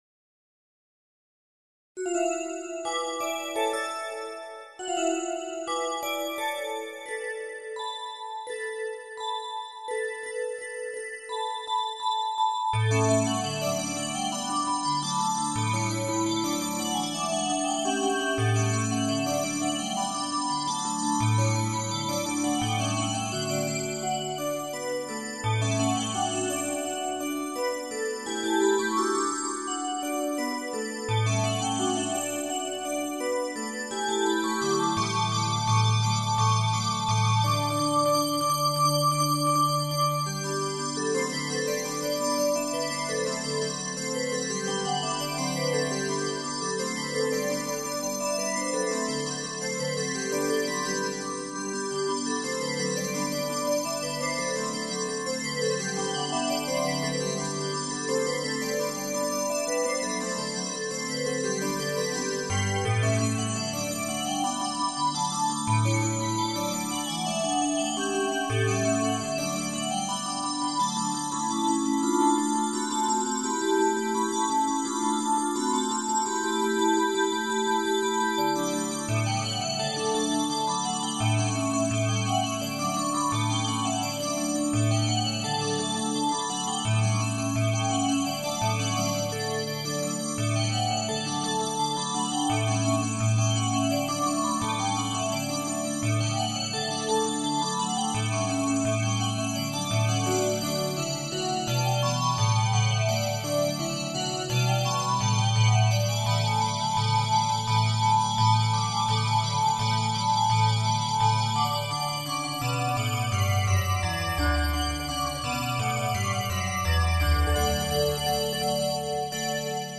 Und es geschieht - die Regie lässt sich nicht lange bitten: Der Geldautomaten dudelt plötzlich - con molto brio - eine schmissige Melodie aus “